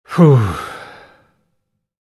Tired Sigh Sound Effect
Tired-sigh-sound-effect.mp3